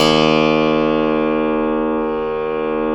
53s-pno02-D0.aif